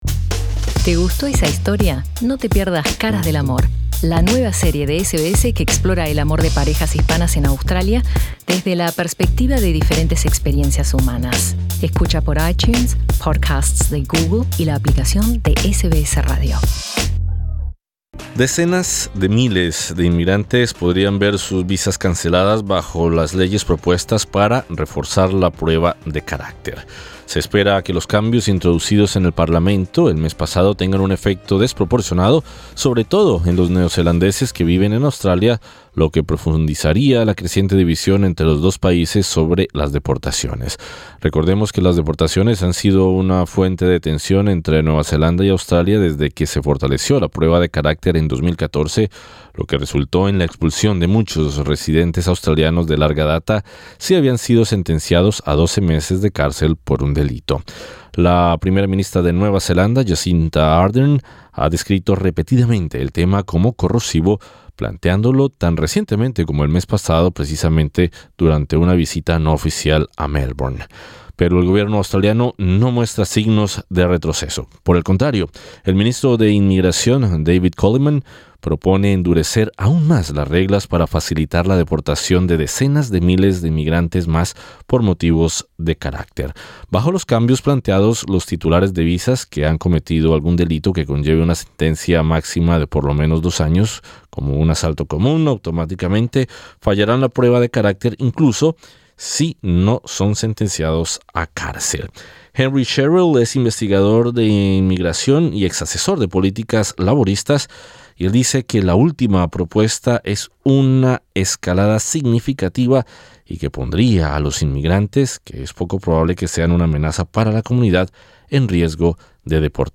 Entrevista con el agente de inmigración registrado